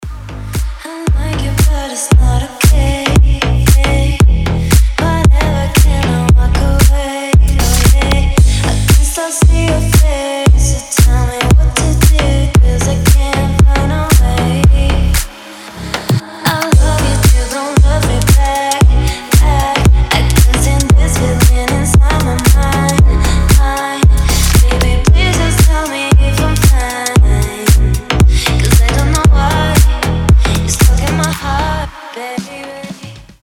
• Качество: 320, Stereo
deep house
женский голос
басы
чувственные
nu disco
Indie Dance
Стиль: deep house, indie dance